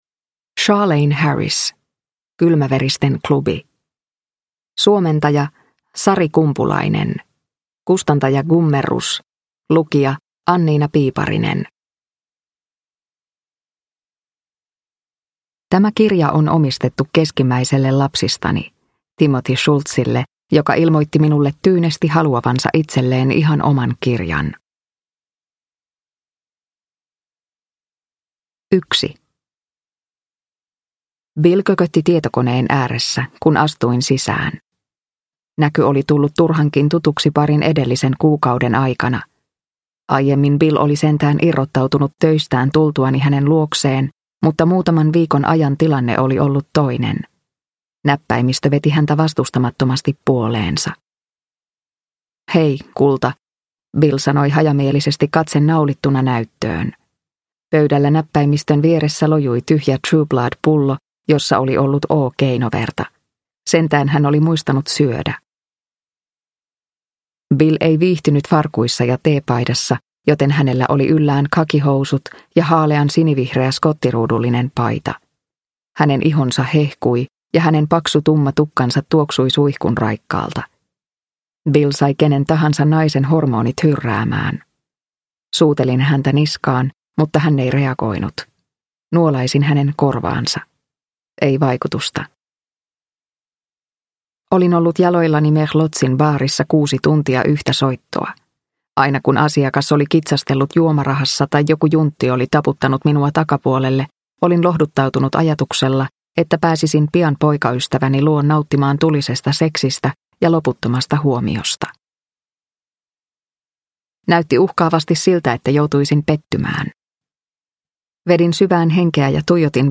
Kylmäveristen klubi – Ljudbok – Laddas ner